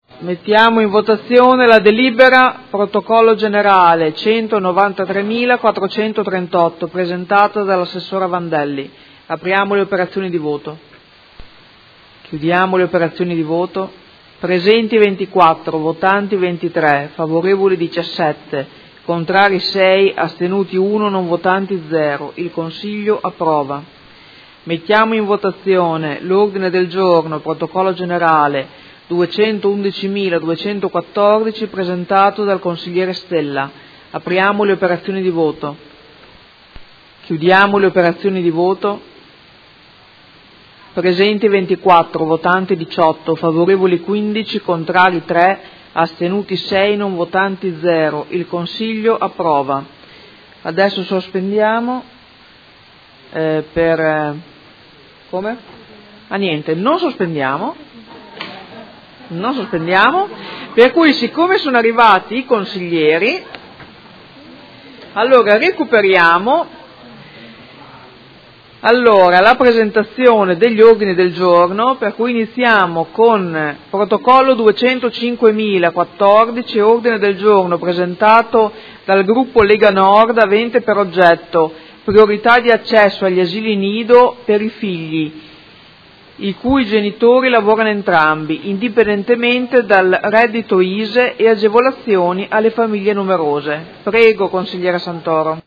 Presidente
Seduta del 20/12/2018.